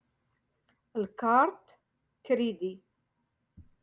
Moroccan Dialect- Rotation Five-Lesson Sixty Four